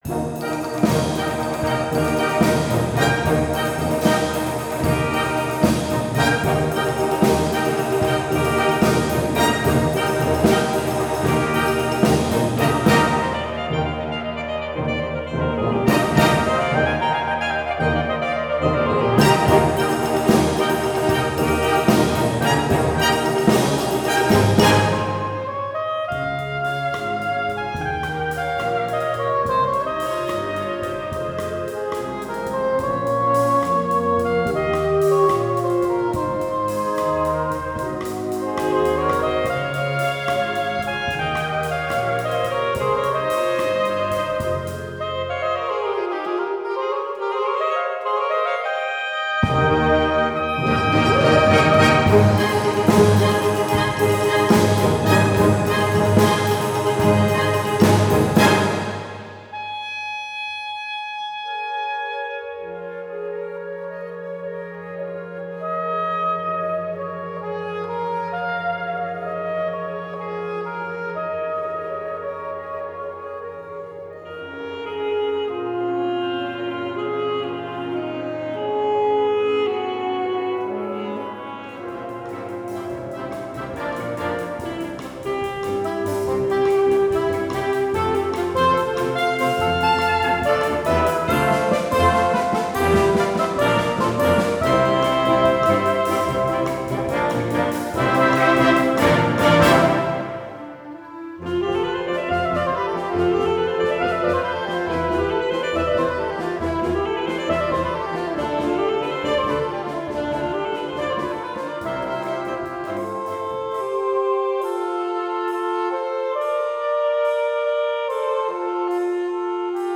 for 2 Saxophones, 7'00", Gr. 4, WB FA